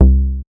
MoogAttack 004.WAV